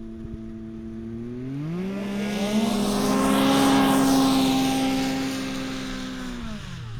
Internal Combustion Snowmobile Description Form (PDF)
Internal Combustion Subjective Noise Event Audio File (WAV)